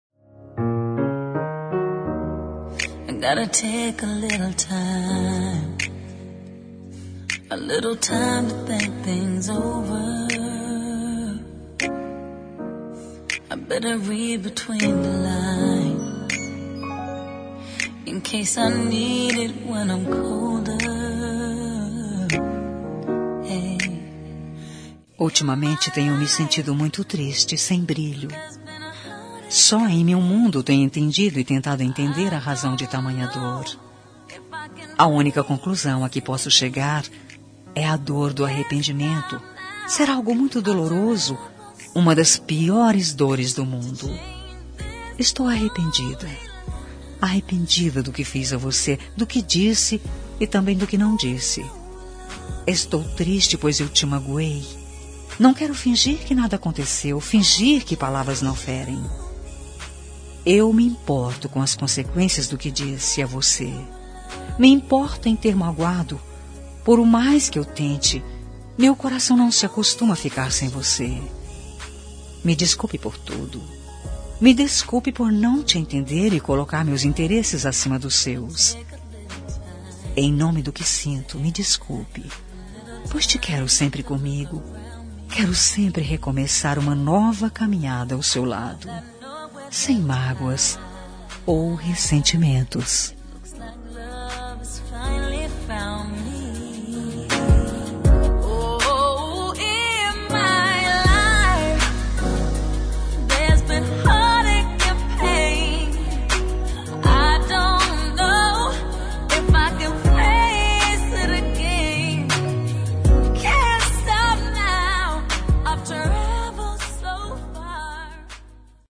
Telemensagem de Desculpas – Voz Feminina – Cód: 356 – Bonita